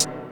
Dre HiHat2.wav